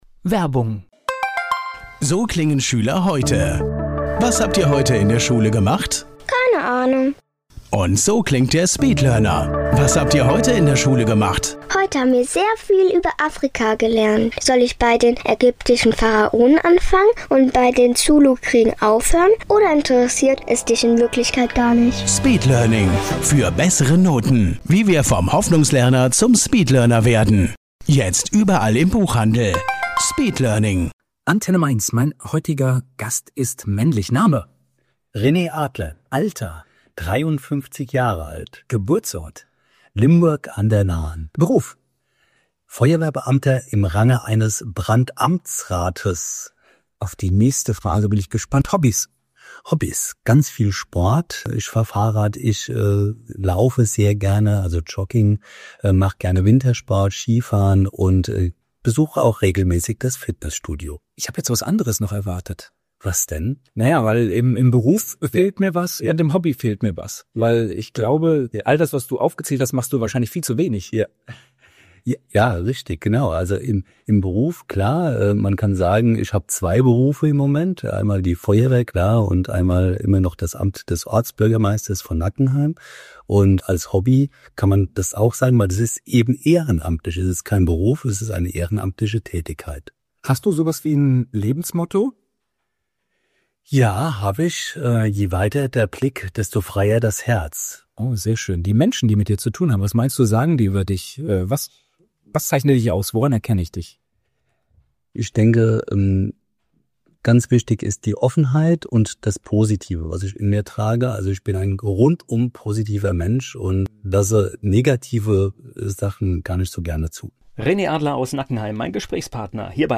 Ein Gespräch über Verantwortung, Motivation und das Leben zwischen Ehrenamt, Einsatzleitung und Kommunalpolitik...